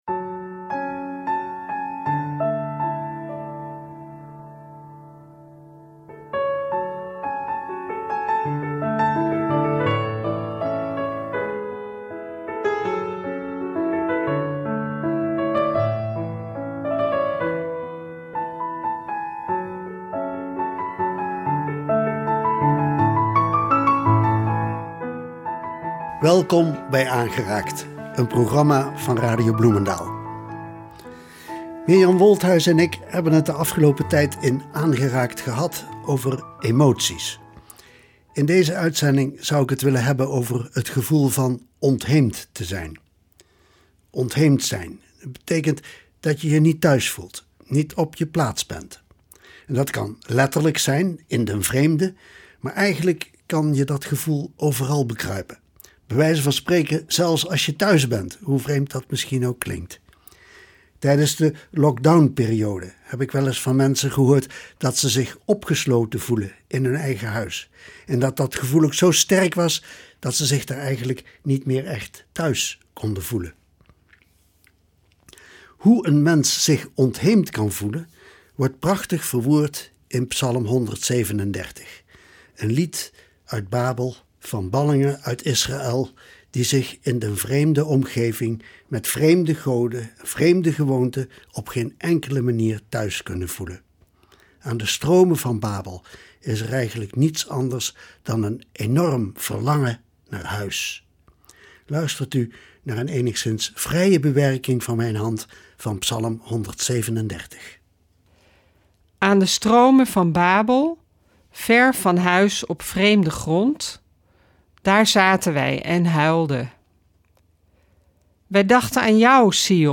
Hier klinkt Psalm 137 vanuit de ballingschap in Babel en komen we thuis met Psalm 126 ‘Als God ons thuisbrengt’.